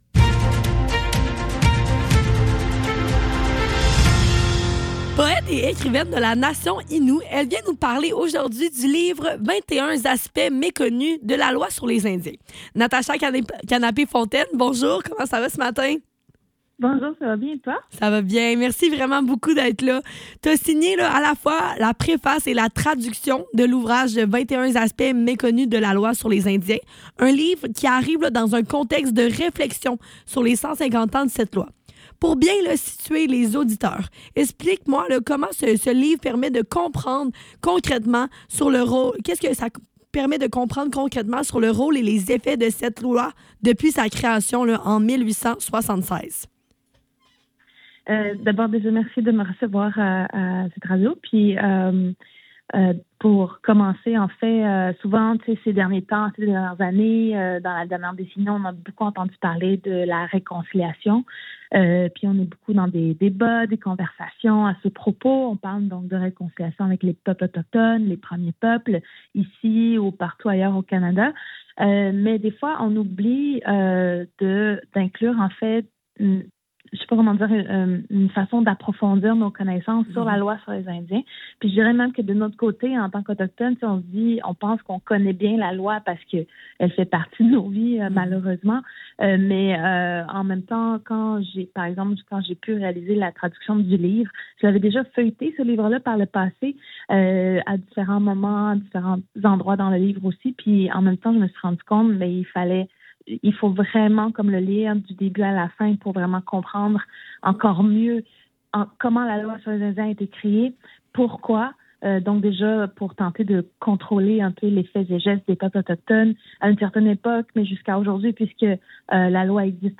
Le Neuf - Entrevue avec Natasha Kanapé Fontaine - 2 avril 2026